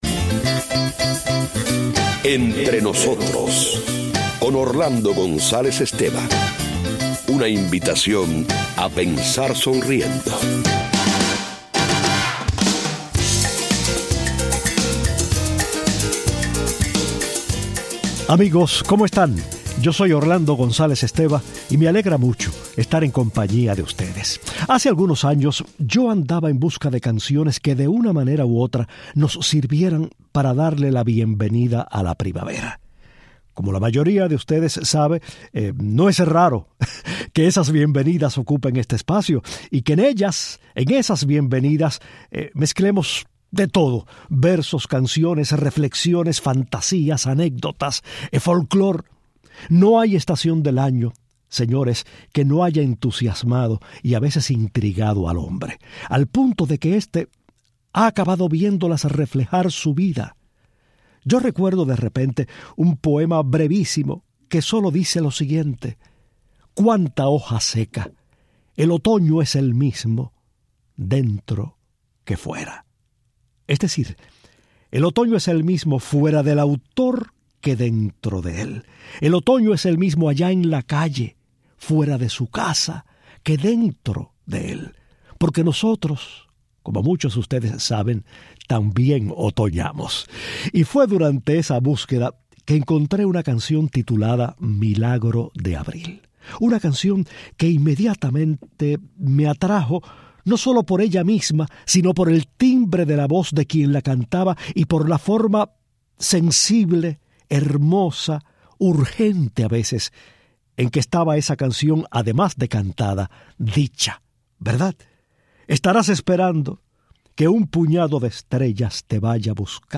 El admirable cantautor chileno, célebre en toda Hispanoamérica, habla de su vida, sus canciones y su estrecha relación con las palabras.